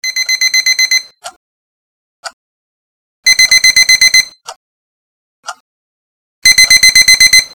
Kategorien Wecktöne